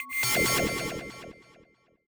K-1 FX.wav